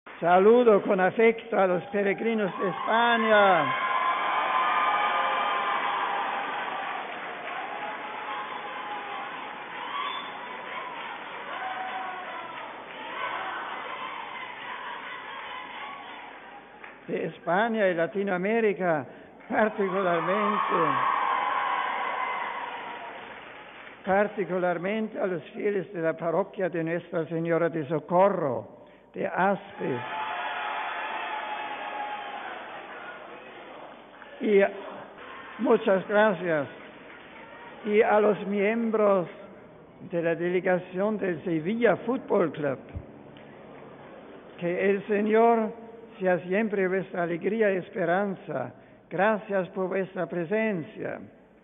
Miércoles, 17 ago (RV).- A las 10.30 de esta mañana, tuvo lugar el encuentro del Santo Padre Benedicto XVI con los fieles y peregrinos que se hicieron presentes en el patio interno del Palacio Apostólico de Castel Gandolfo para asistir a la tradicional Audiencia General de los miércoles.
Antes de finalizar el encuentro con los fieles, Benedicto XVI ha saludado como siempre en varias lenguas.